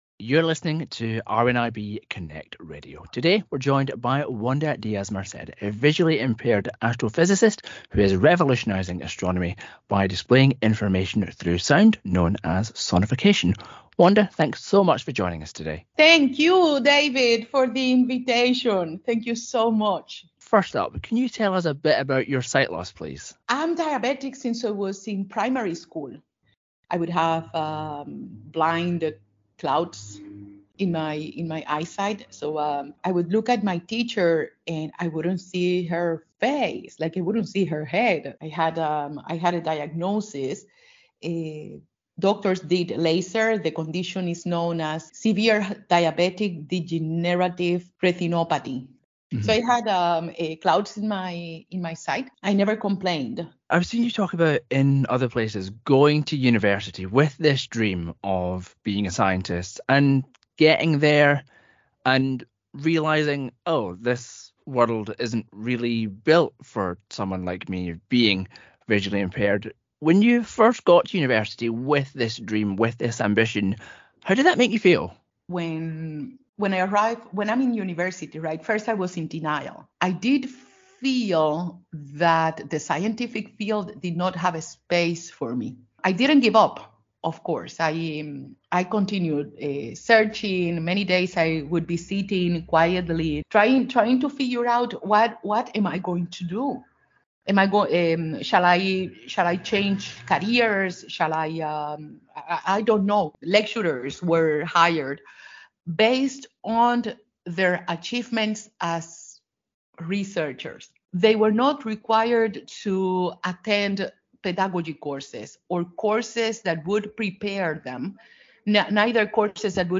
Sight Loss and Sonification: An Interview